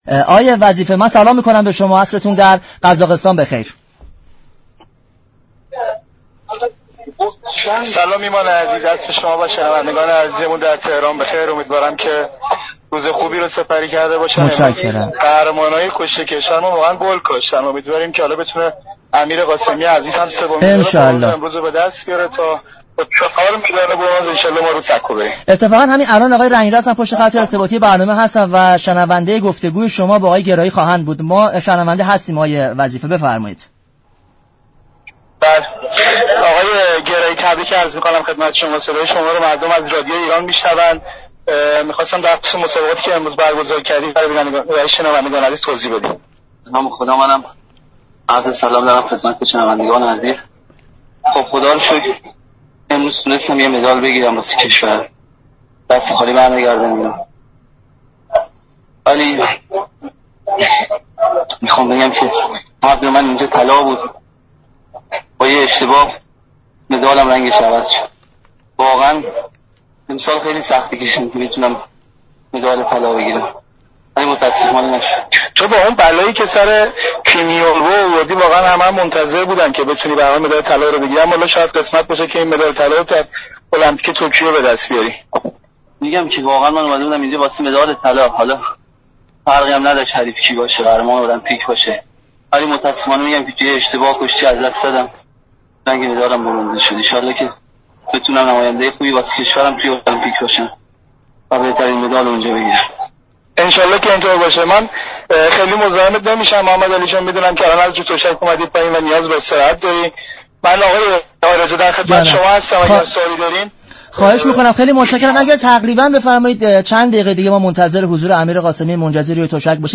به صورت تلفنی در برنامه ورزش ایران حاضر شد